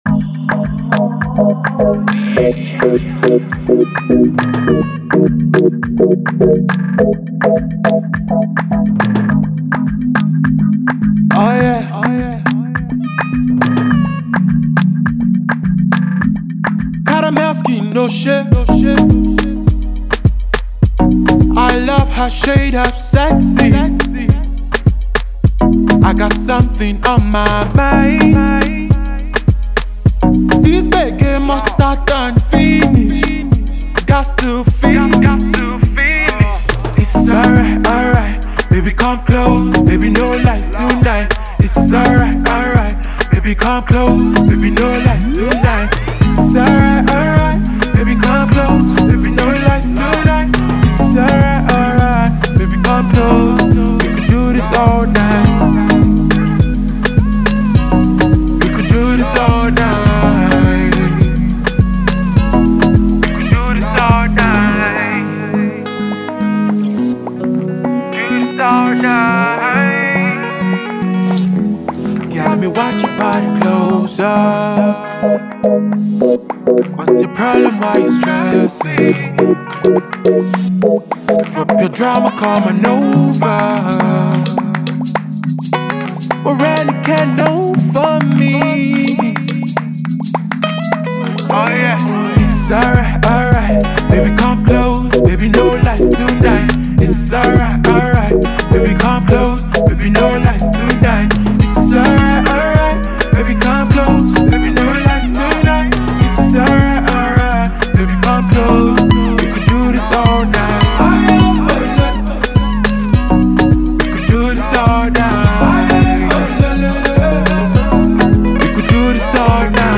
Afro-fused sound